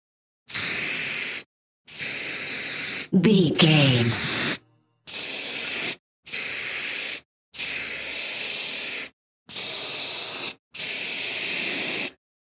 Graffiti aerosol spray medium
Sound Effects
urban
foley